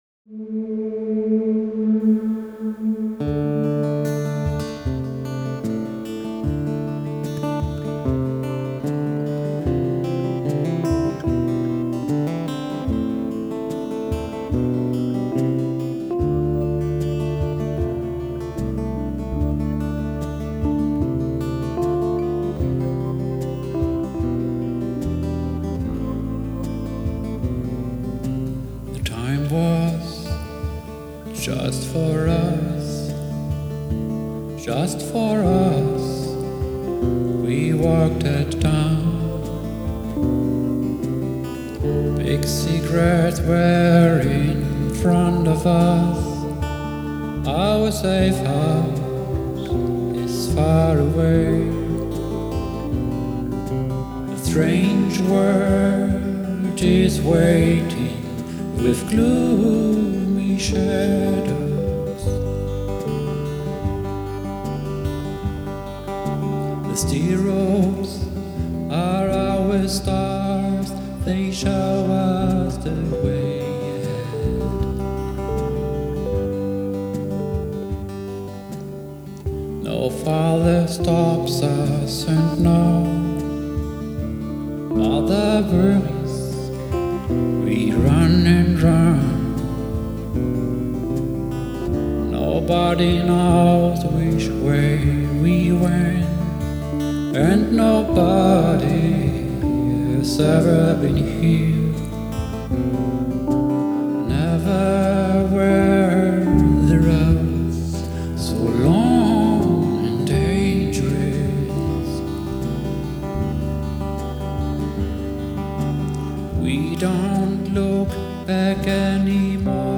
Die Aufnahmequalität ist nämlich nicht sonderlich gut. Aufgenommen mit einem einfachen Mikrofon zu Hause, sind die Tiefen und Nuancen über einfache Abspielgeräte, wie Handy oder Tablet, nicht sonderlich zu hören.
Einiges klingt auch schief, hatte dann aber keine Lust, alles noch einmal aufzunehmen.